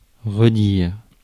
Ääntäminen
IPA: [ʁə.diʁ]